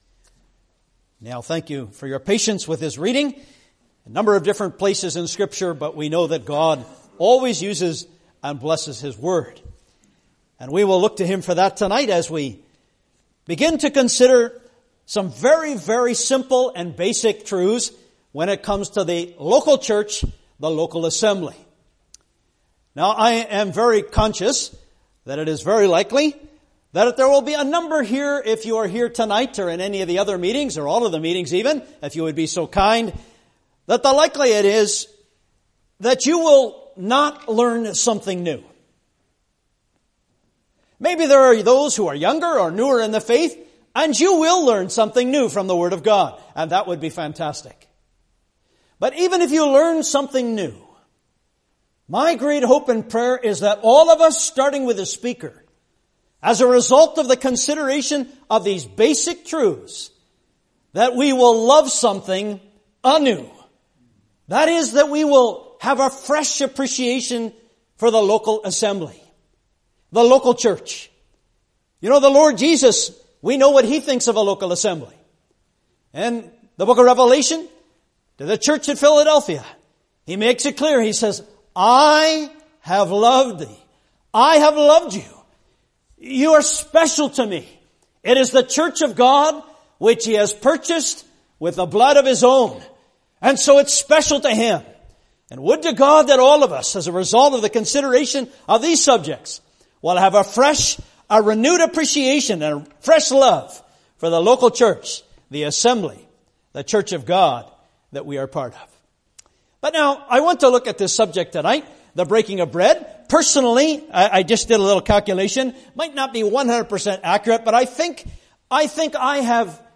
Readings: Mark 15:22-25, Acts 2:41-46, 20:6-7, 1 Cor 10:16-17, 11:23-34 (Message preached 28th Feb 2020)